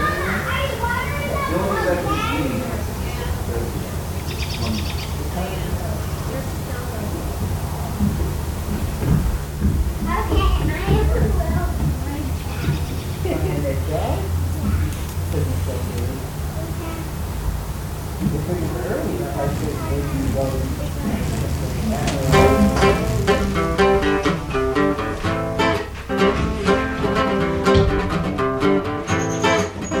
Les arrangements sont luxuriants, les paroles incisives.
Rock et variétés internationales